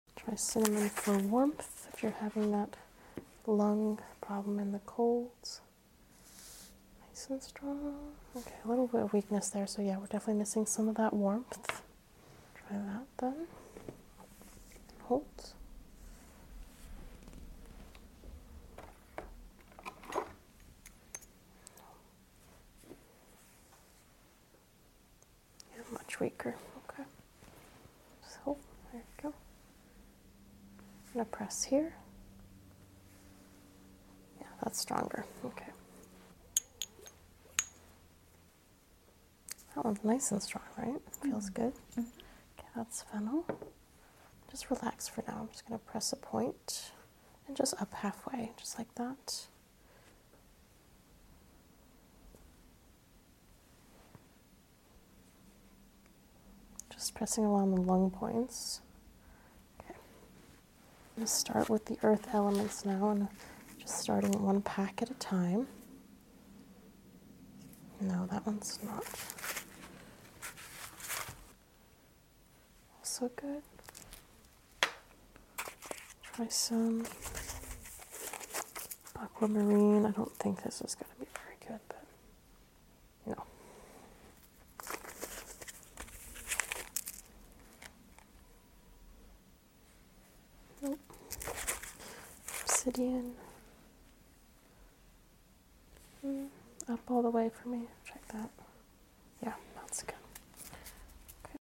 *sleep incoming* ASMR applied kinesiology/energy sound effects free download